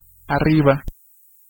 Ääntäminen
IPA : /ʌp/